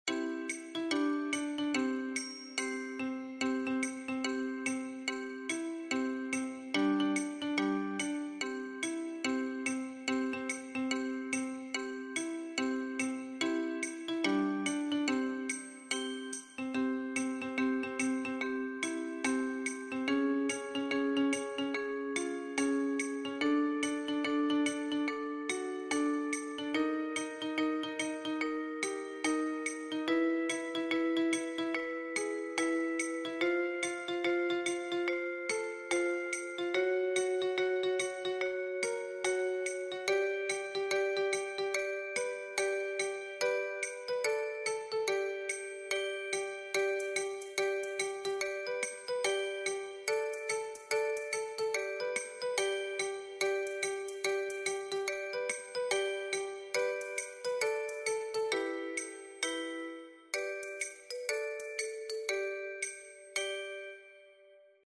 classic African American Spiritual… Watch as the melody climbs the scale!